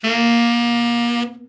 Index of /90_sSampleCDs/Giga Samples Collection/Sax/SAXOVERBLOWN
TENOR OB   5.wav